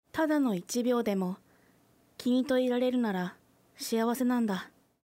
ボイス
女性